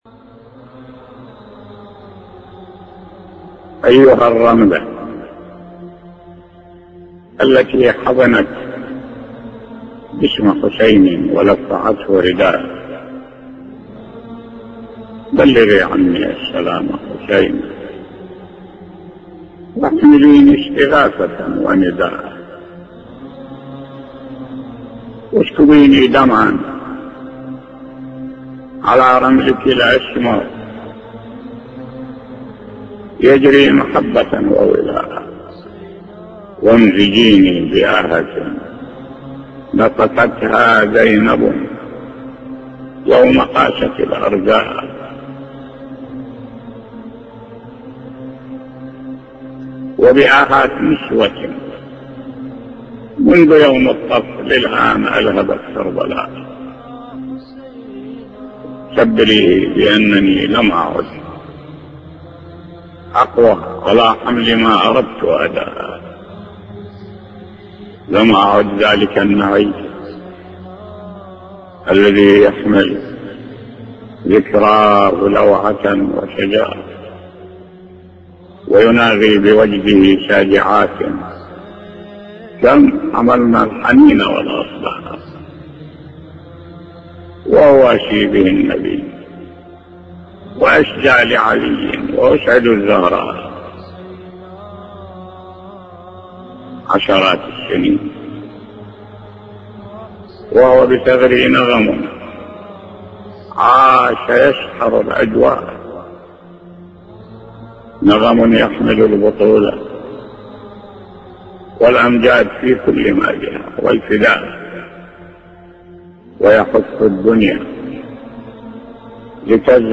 وهنا بصوت الشيخ الوائلي رحمه الله